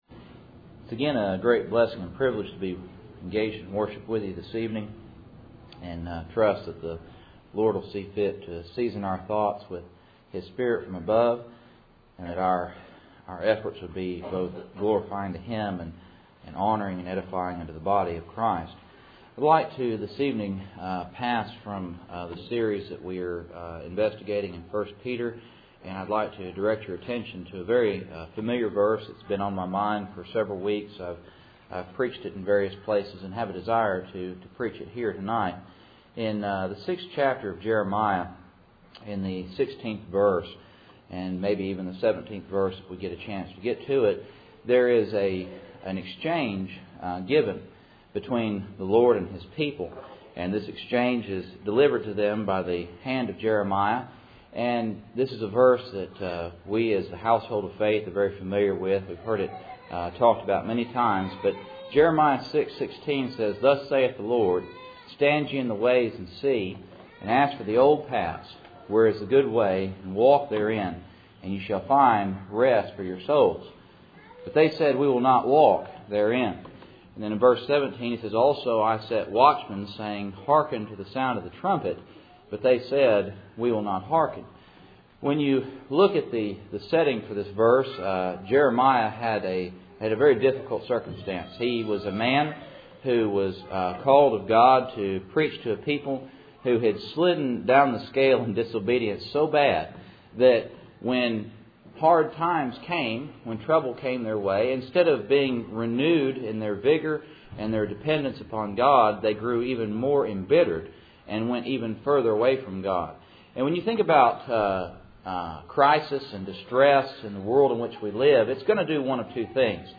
Passage: Jeremiah 6:16 Service Type: Cool Springs PBC Sunday Evening